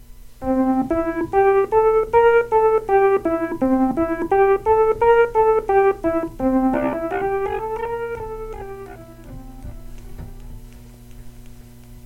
Sample 9: This sample illustrates the single triggering of the effects. The first two arpeggios were played stacatto, and the last legato.